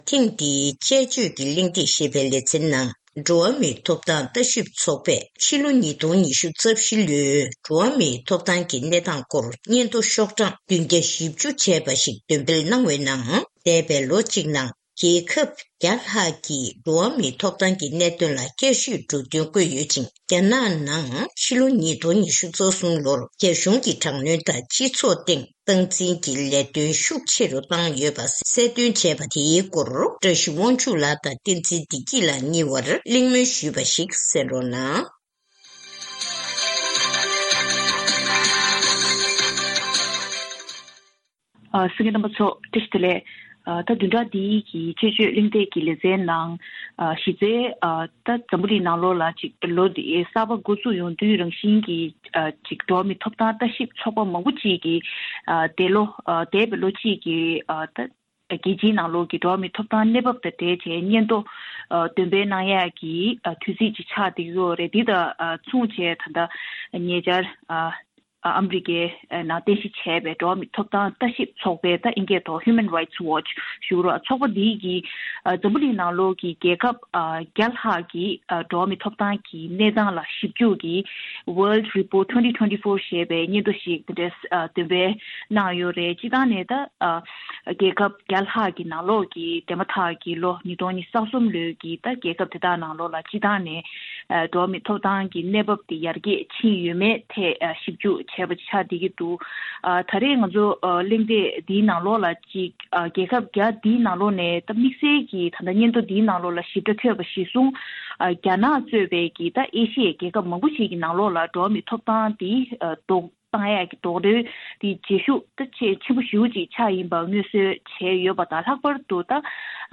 ཐེངས་འདིའི་དཔྱད་བརྗོད་གླེང་སྟེགས་ཀྱི་ལས་རིམ་ནང་འགྲོ་བ་མིའི་ཐོབ་ཐང་ལྟ་ཞིབ་ཚོགས་པའི་ལོ་འཁོར་སྙན་ཐོའི་སྐོར་དཔྱད་གླེང་།